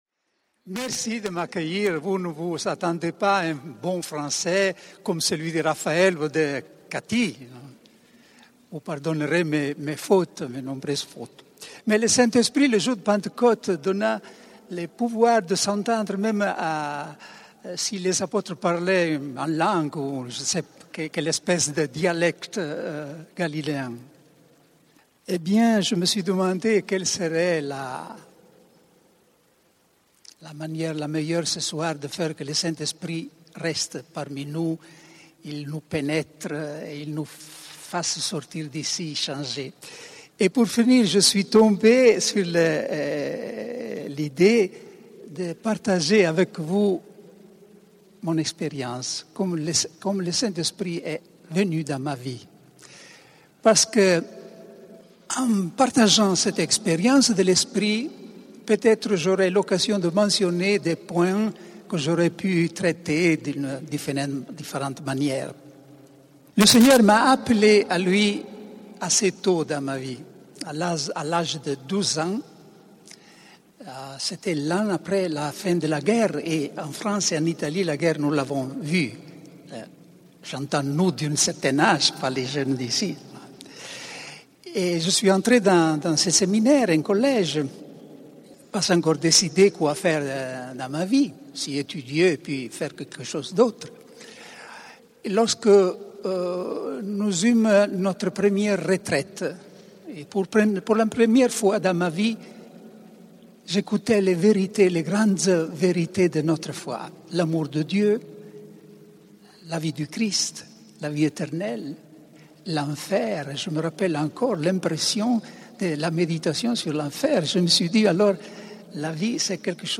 CD Petites Conférences Spirituelles, CD Mission et évangélisation, CD Témoignages
Enregistré à Lisieux lors de la session de la Communauté des Béatitudes en 2008